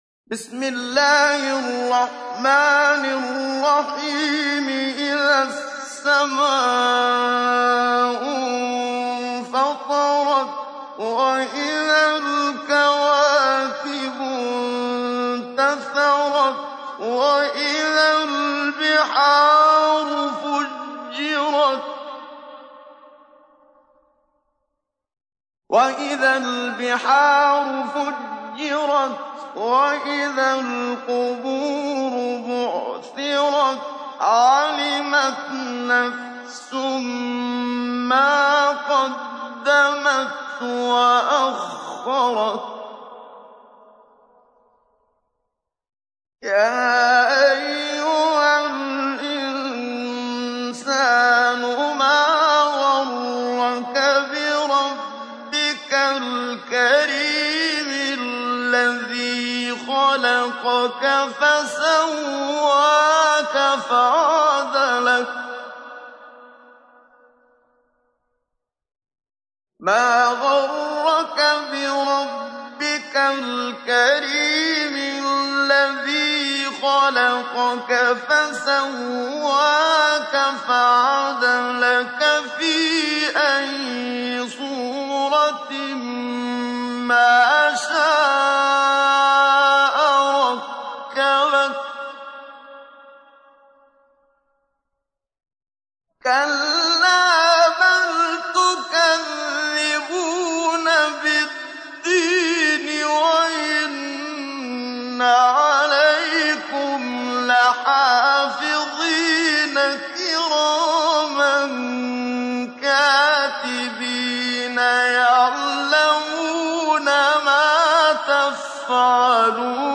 تحميل : 82. سورة الانفطار / القارئ محمد صديق المنشاوي / القرآن الكريم / موقع يا حسين